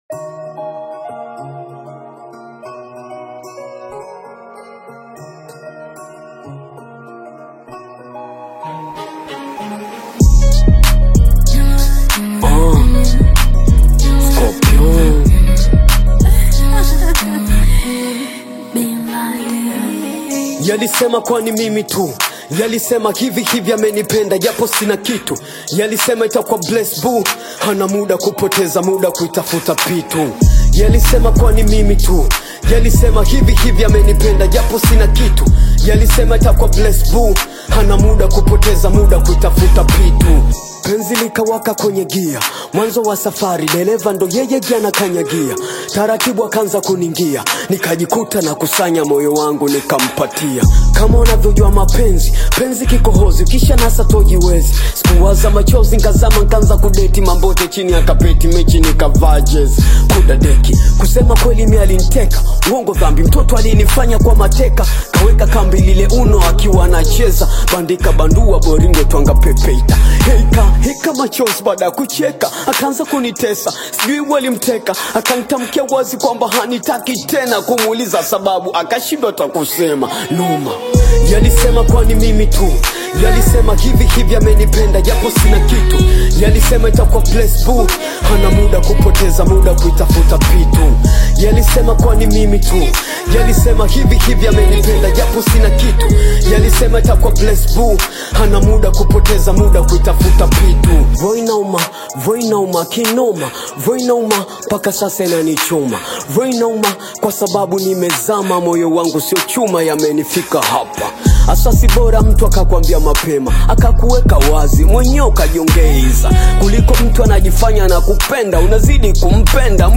hard-hitting Tanzanian Hip-Hop single